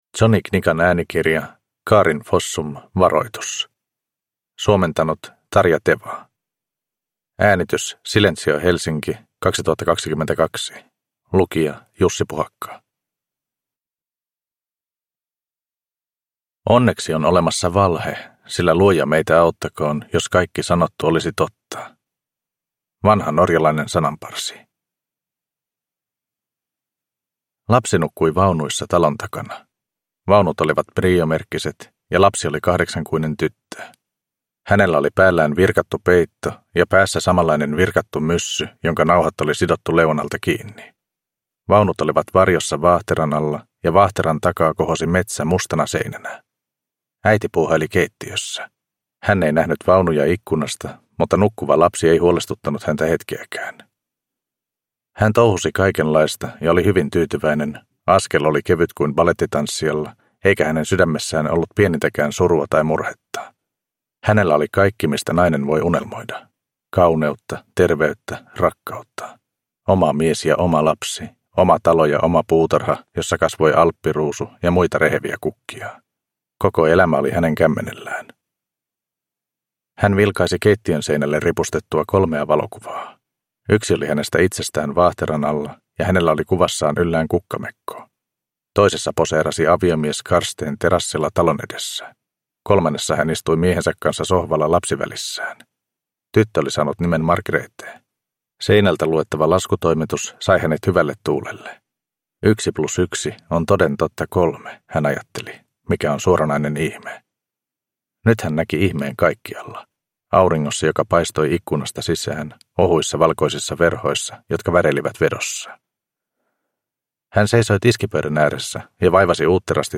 Varoitus – Ljudbok – Laddas ner